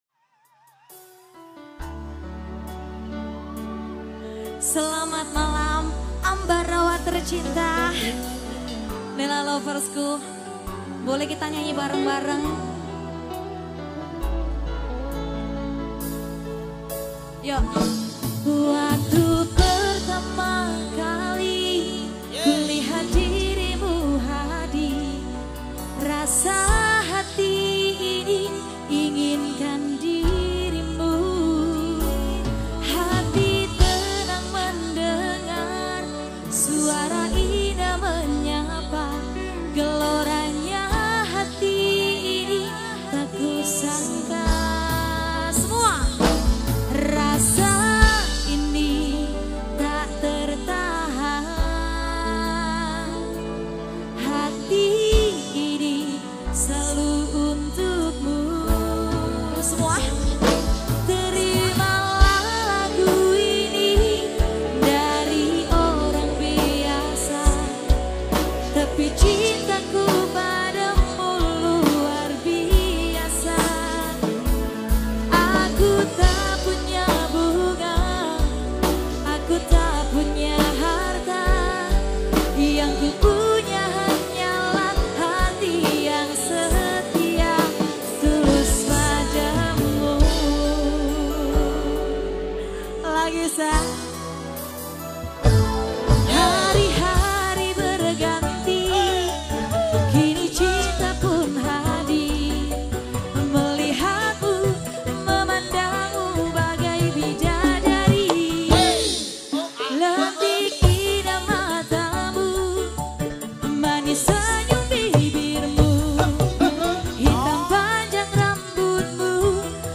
Dangdut Koplo
Vokal
:Mp3 Cover